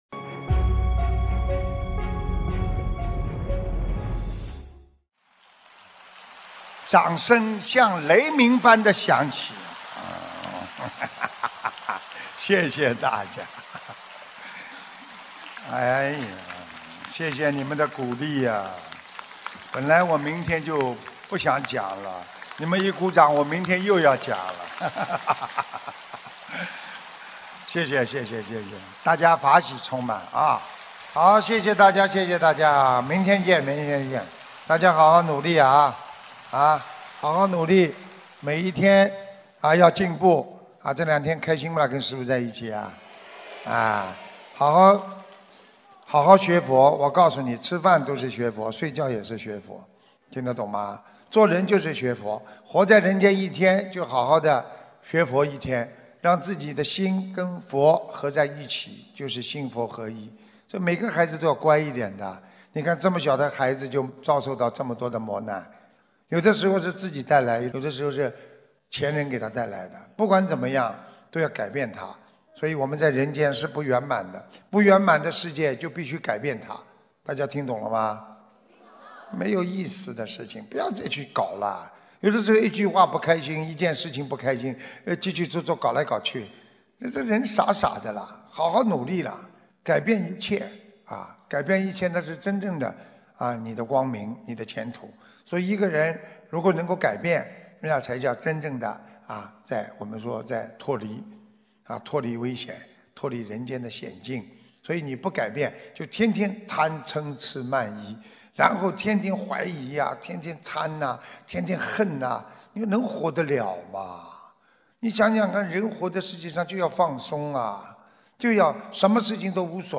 2019年11月9日新西兰世界佛友见面会结束语-经典感人开示节选